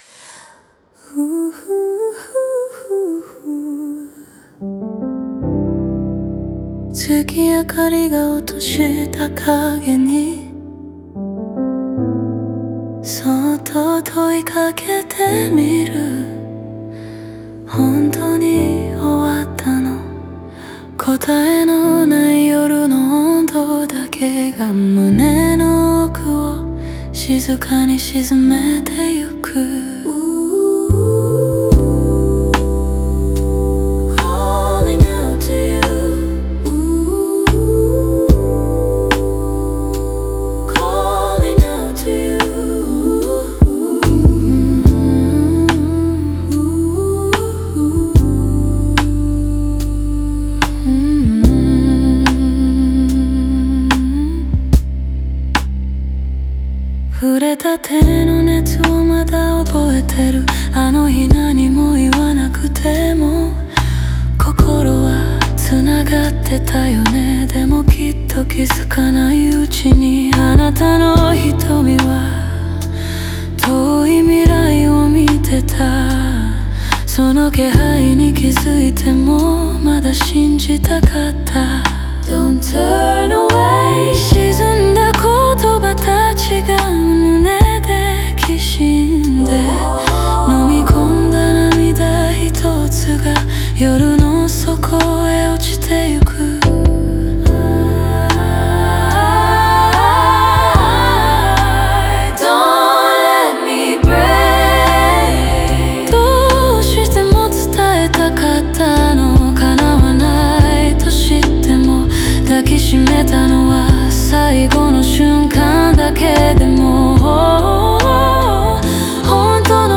オリジナル曲♪
語りかけるようなトーンで始まり、主人公が胸に沈めた想いを一つひとつ噛みしめるように物語が進む。